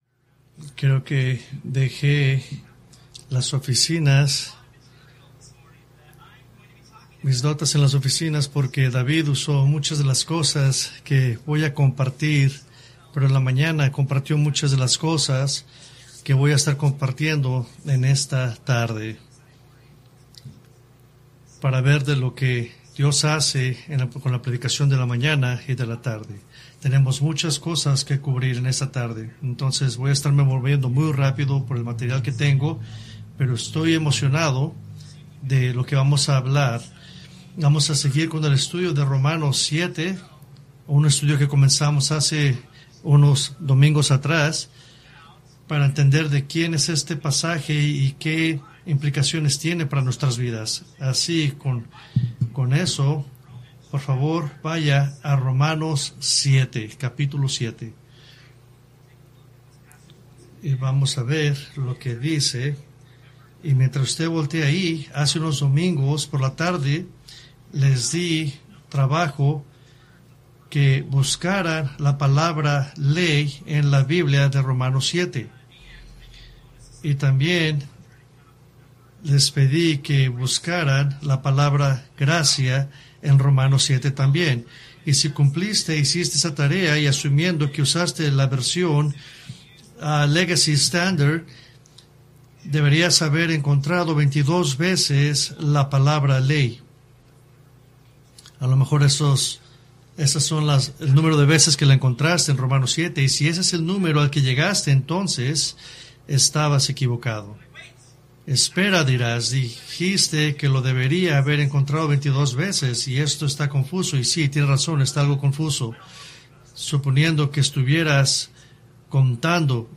Preached July 7, 2024 from Romans 7